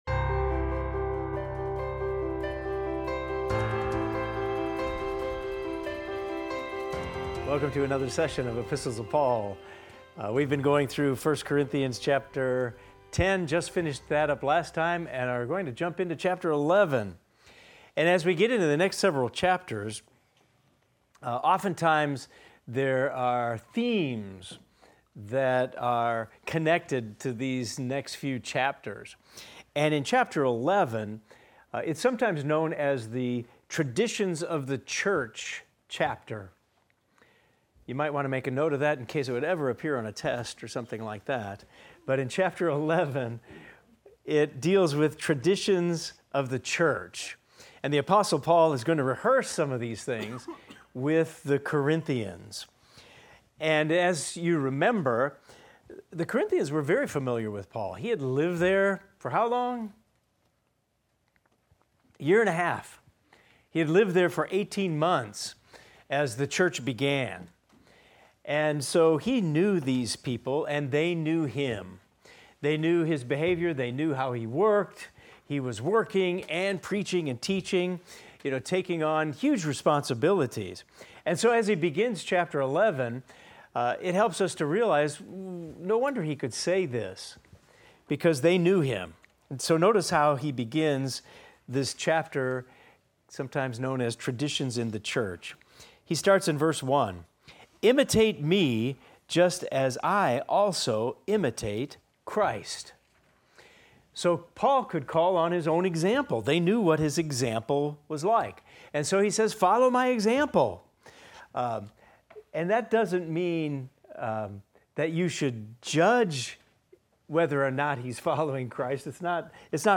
In this class we will discuss 1 Corinthians 11:1–16 and examine the following: Paul discusses gender roles and decorum in worship. He commends imitating Christ but addresses head coverings for women in public worship.